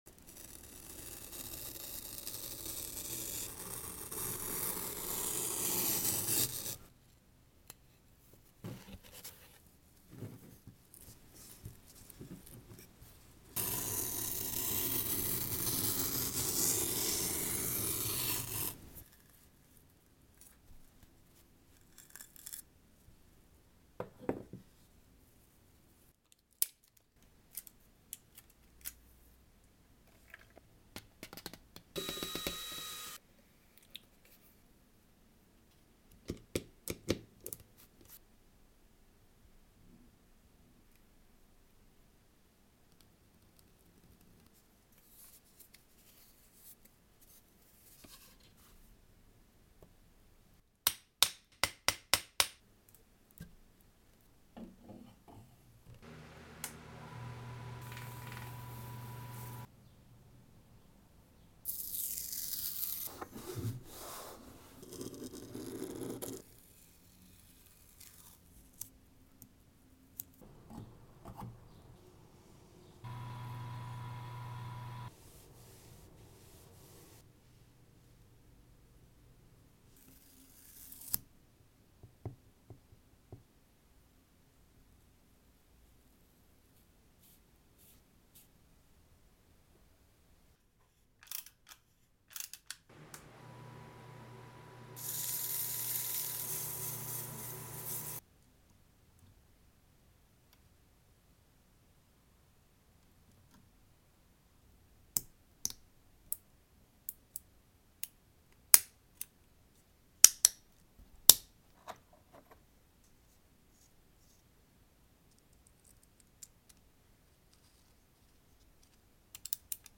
. edit: if you saw this earlier, this is a reupload without the voiceover, i had edited a version with just the process sounds, and realized i liked it more that way!